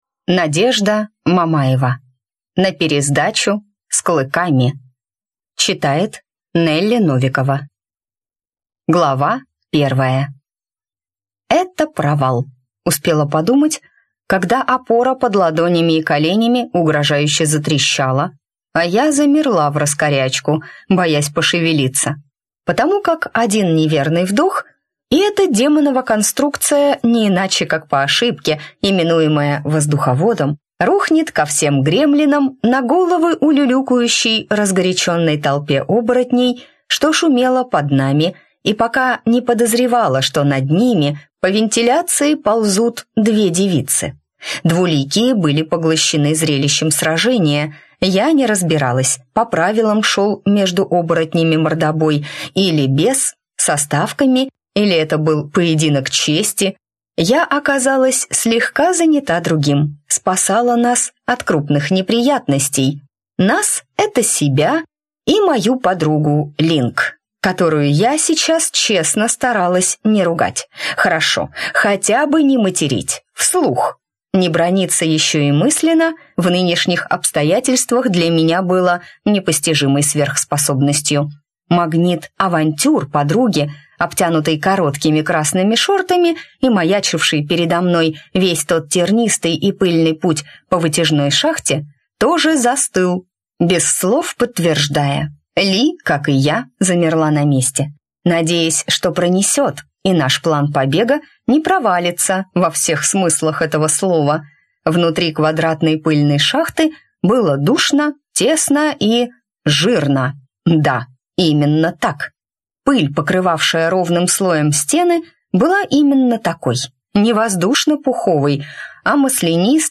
Аудиокнига На пересдачу – с клыками!